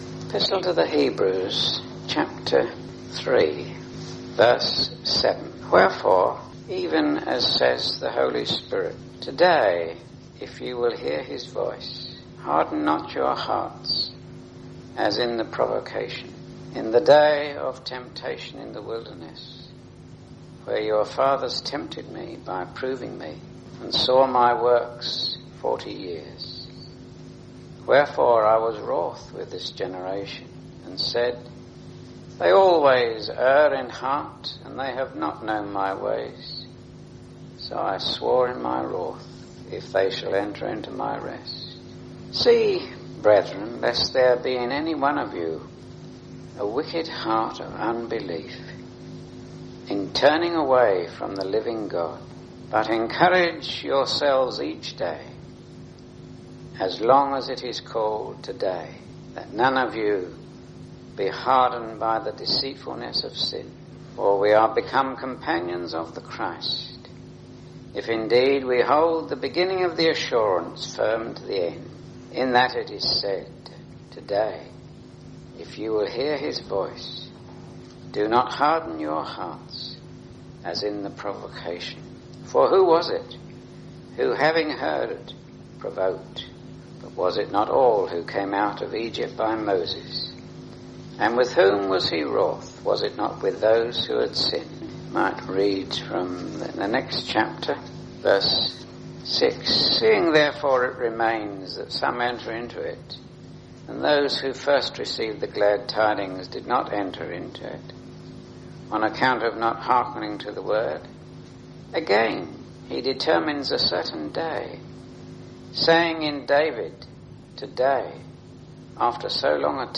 Listen to this preaching and discover how you can be forgiven of your sins and given hope beyond this world through the person of the Lord Jesus Christ.